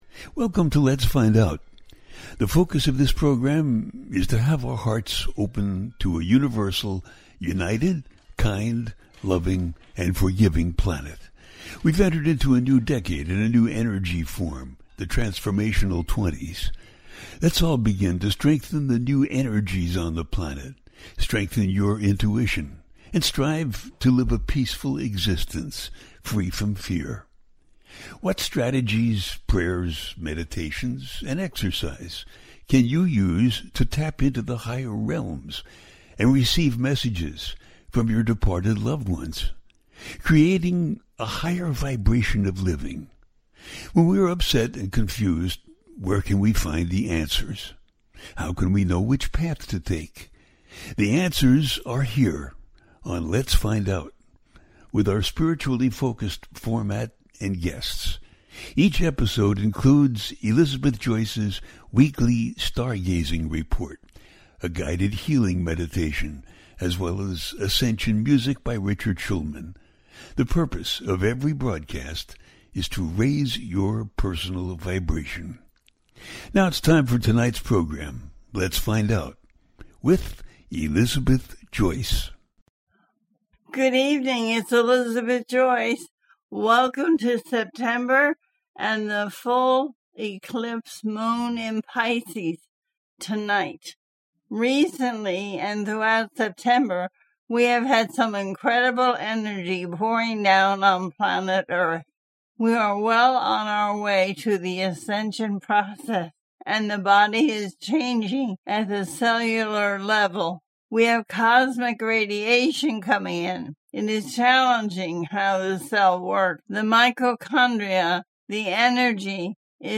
The Shape Chaning Energies Of September 2025 - A teaching show